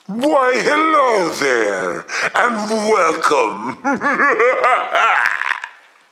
Funny evil cartoon voice with laugh
Category 🗣 Voices
cartoon character funny goofy Halloween human laugh scary sound effect free sound royalty free Voices